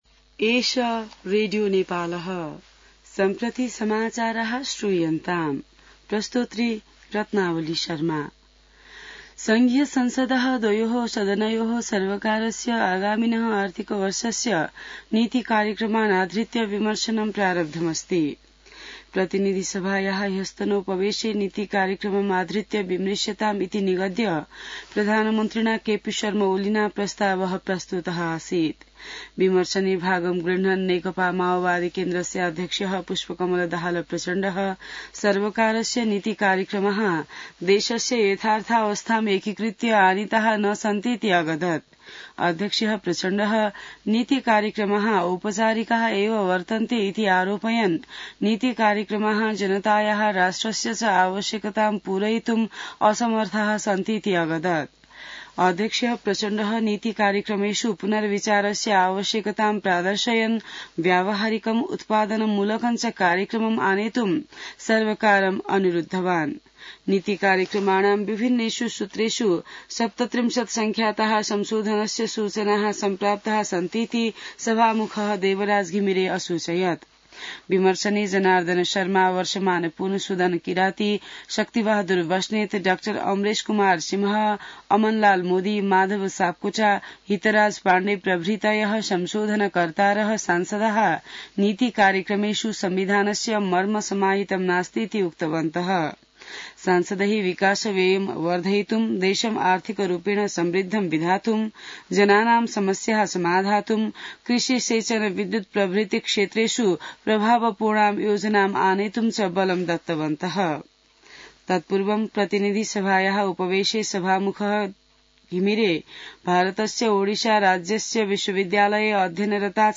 संस्कृत समाचार : २३ वैशाख , २०८२